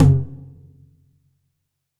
9HITOM.wav